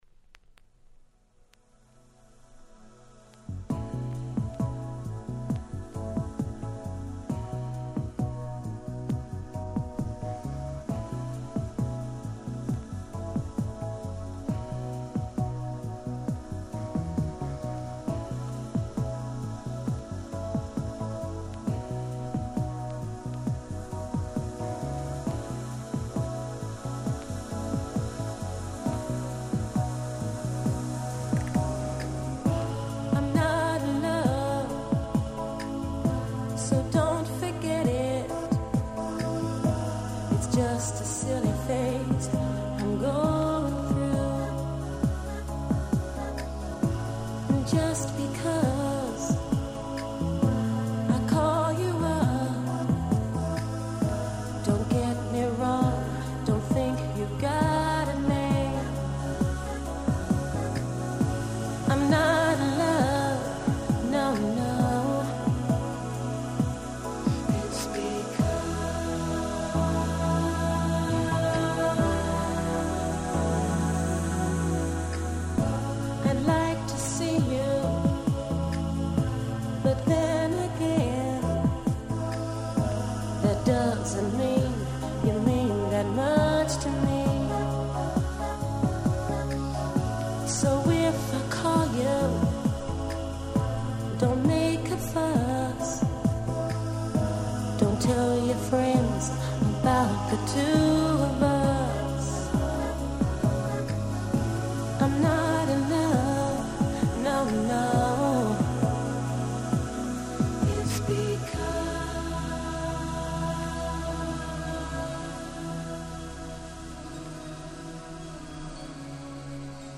96' Nice R&B LP !!